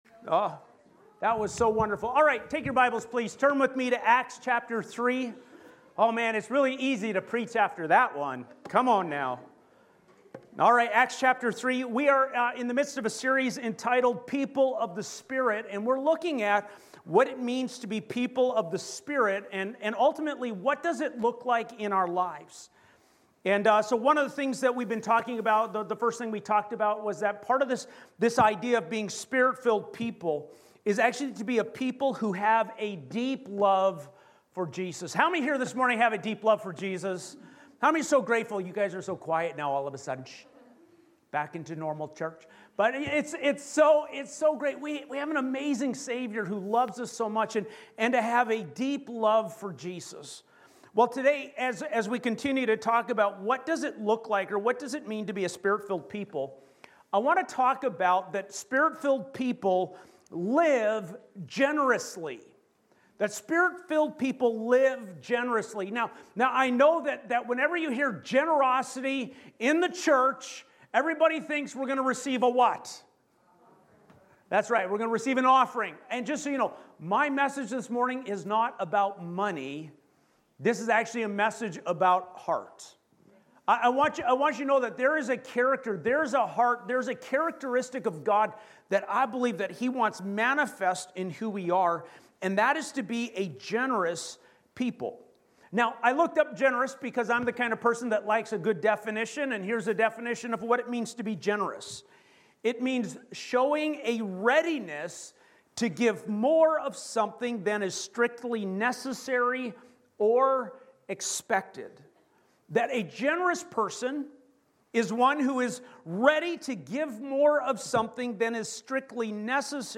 Sermons | Asbury Church